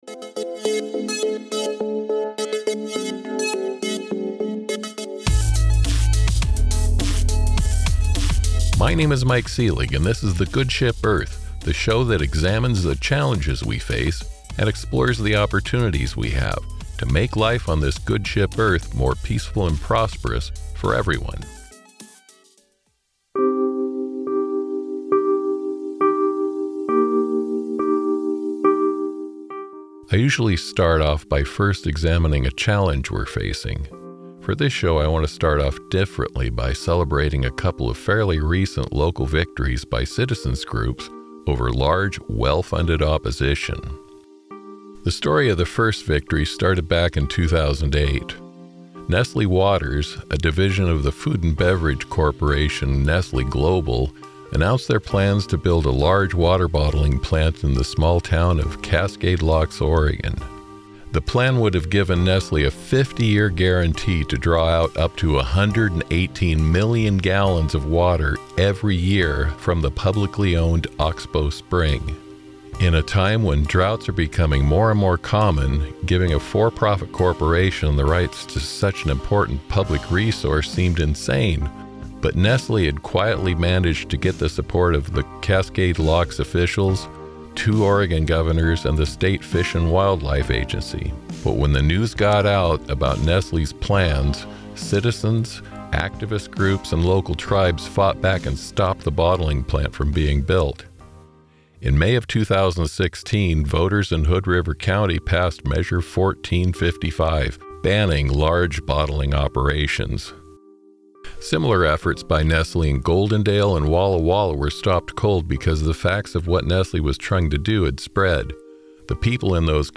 The following are excerpts from our conversation about the massive changes in the media landscape and how newspapers are particularly hit hard by big tech and vulture capitalists.